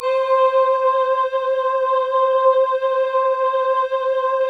Index of /90_sSampleCDs/Optical Media International - Sonic Images Library/SI1_Soft Voices/SI1_Stackedvoice